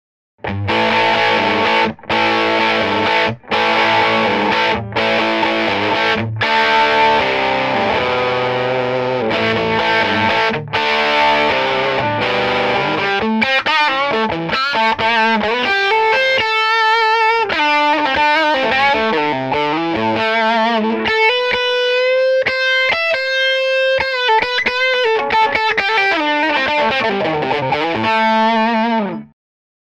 The Tweed is a rocking amp, but it's really hard to capture that Marshall sounding overdrive with a microphone on the speaker.
I spent a couple of hours today working with microphone placement and outboard EQ, eventually recording a brief clip (without any EQ) that pretty faithfully captures the sound of the Tweed Deluxe cranked up.
Test clip signal chain: 1968 Gibson 335 (settings: bridge pickup, volume and tone on 10) into my old Tweed Deluxe with a re-coned 1960 Jensen P12Q speaker and low-pass mod (settings: bright channel high-gain input, volume 10, tone off, unused channel off). The microphone is a Shure SM-57 about 6 inches in front of the amp, the capsule midway between the dust cap and edge of the cone, pointing 90 degrees (perpendicular) away from the dust cap (I still had some edge to deal with and this position provided a signal that is closer to what the amp really sounds like live in a room).
There are no effects or other signal processing, it's just a dry signal, single recorded track, center-panned.